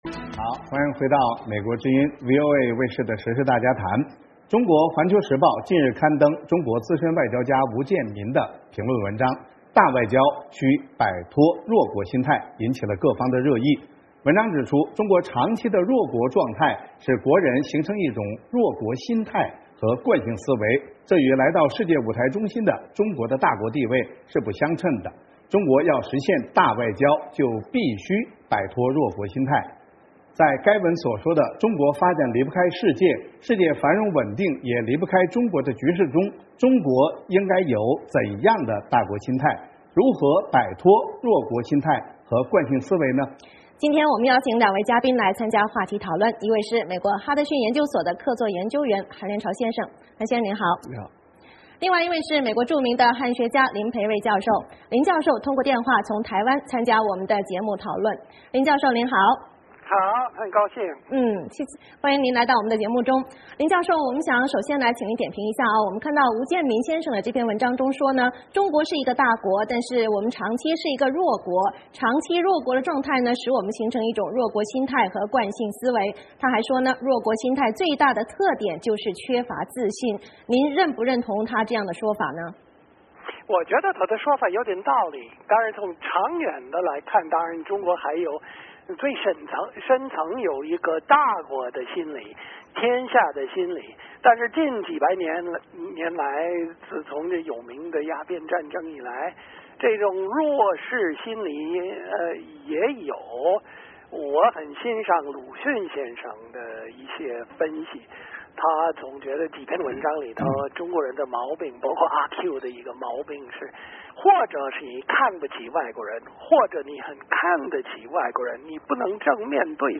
今天我们邀请两位嘉宾来参加话题讨论
另外一位是美国著名的汉学家林培瑞教授，林教授通过电话从台湾参加我们的节目讨论